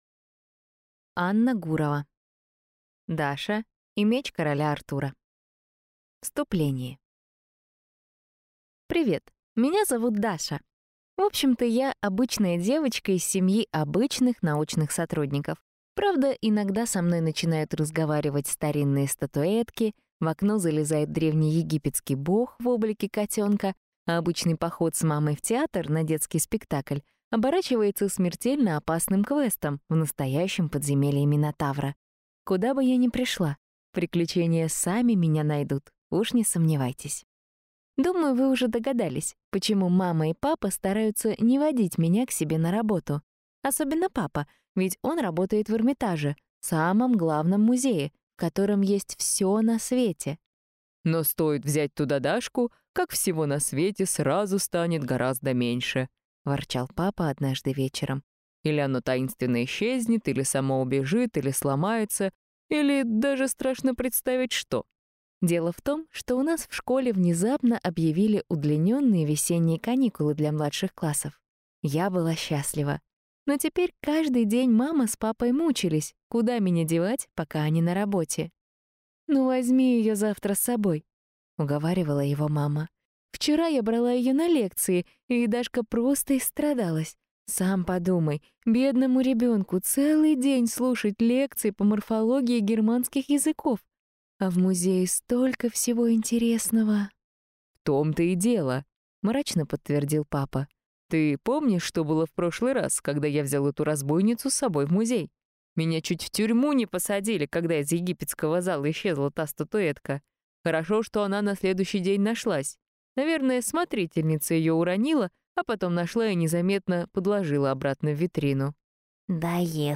Аудиокнига Даша и меч короля Артура | Библиотека аудиокниг